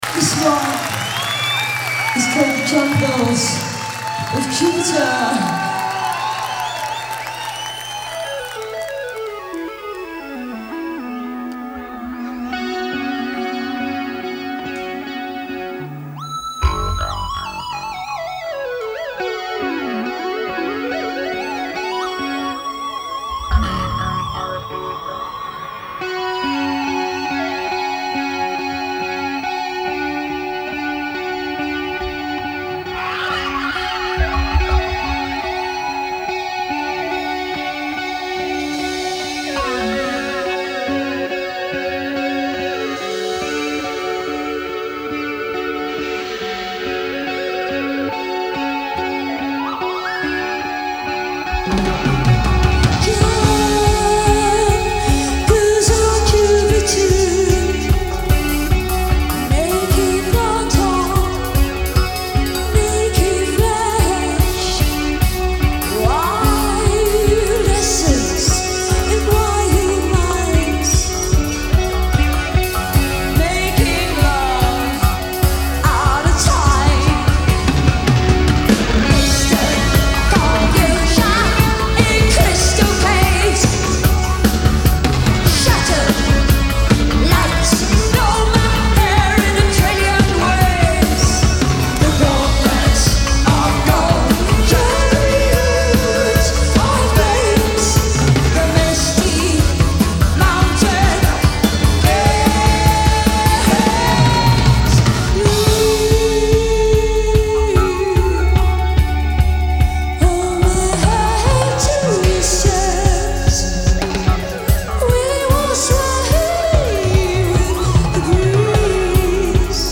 Genre : Rock
Live at Theatre Royal, Drury Lane